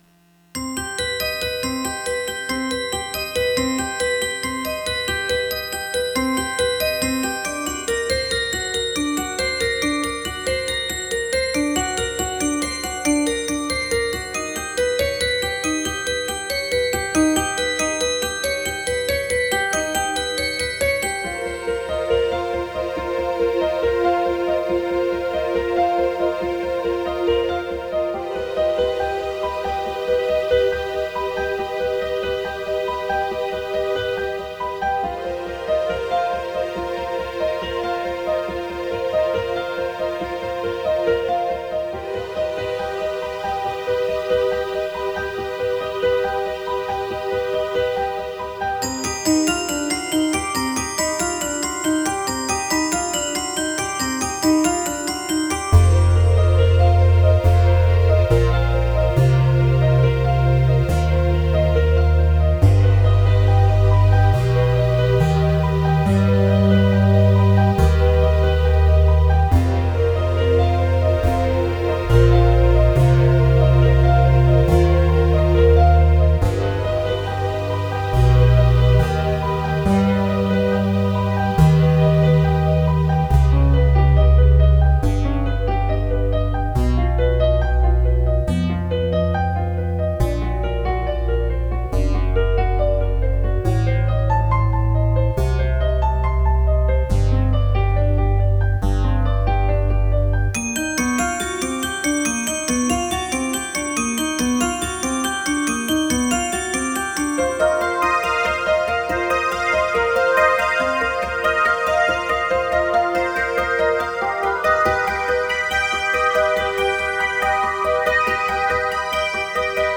I’ve gone about happily making sequencer music and only later realizing that it doesn’t conform to the rules of sequencer music.